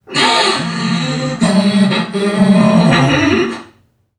NPC_Creatures_Vocalisations_Robothead [62].wav